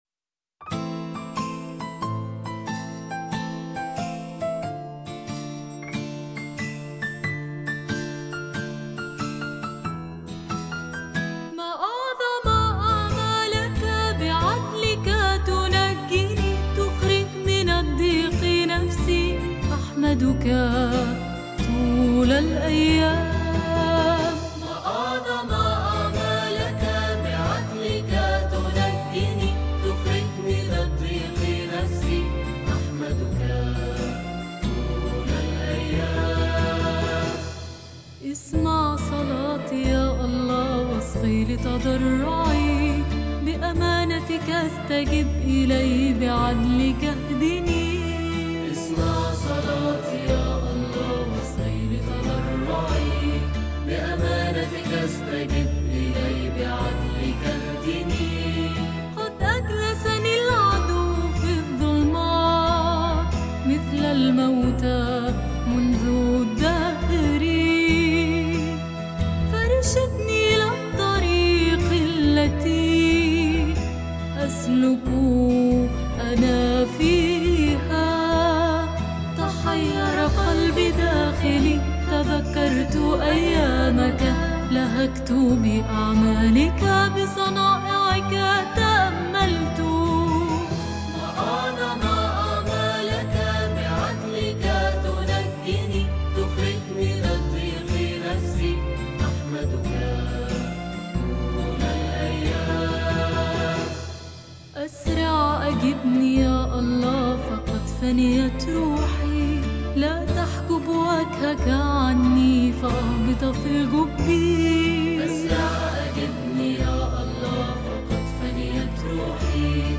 كورال
كمان
عود
جيتار